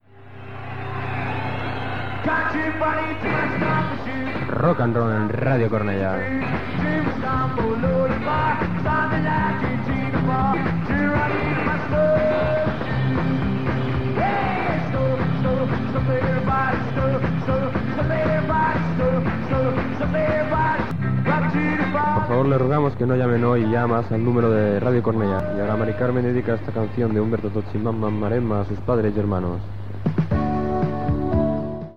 Gènere radiofònic Musical